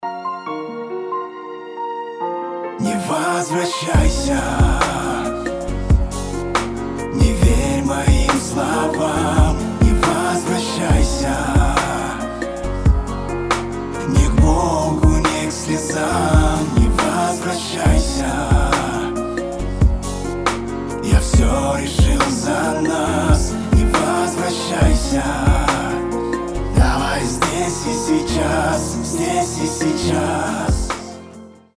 • Качество: 320, Stereo
лирика
грустные
медлячок
печальные